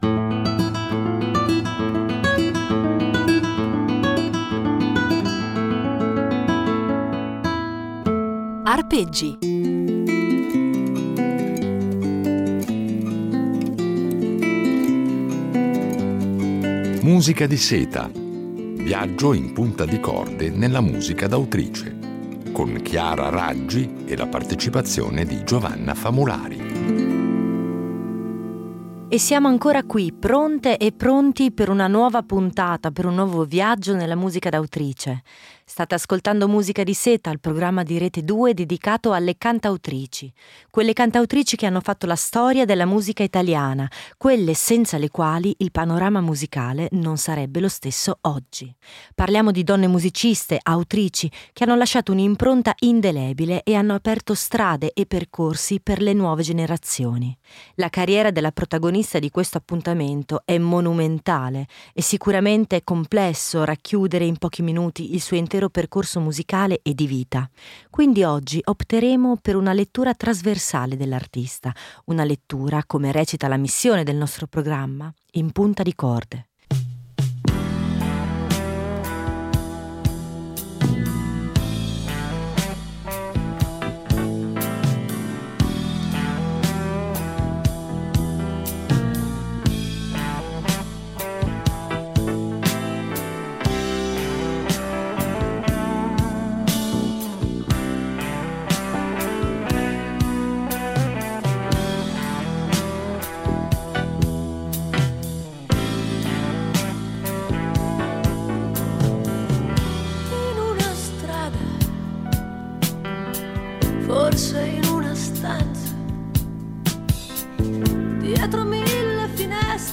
Una serie di Arpeggi impreziosita dalle riletture originali di un duo, ancora inedito